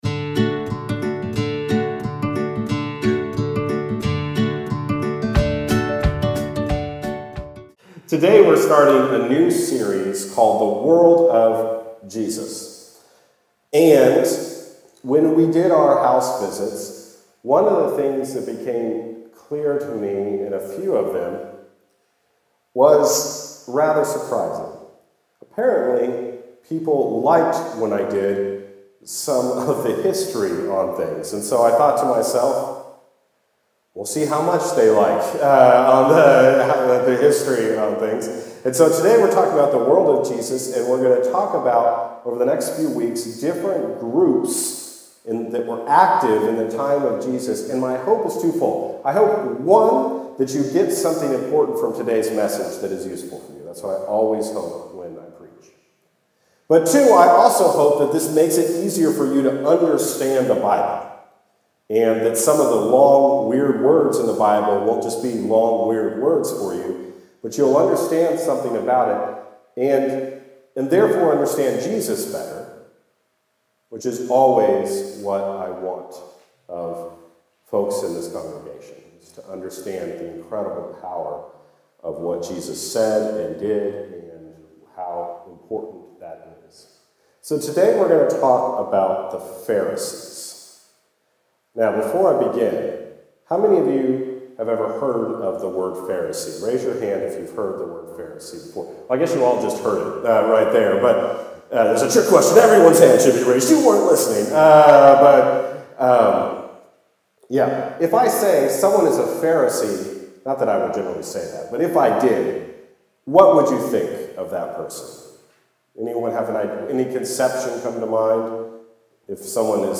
Guest Speaker
Current Sermon